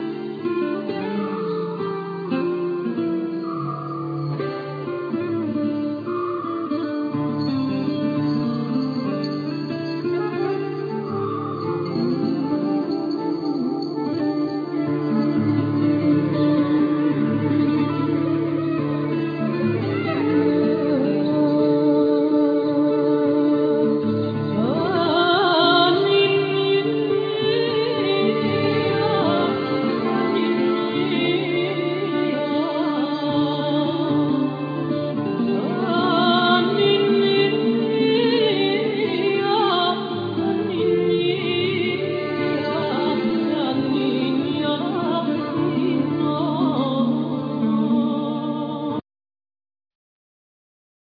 Vocal
Solo guitar,Harp,Mandola
Acoustic guitar
Keyboards
Double bass
Flute,Bottles
Percussions
Alto & soprano saxes
Melodeon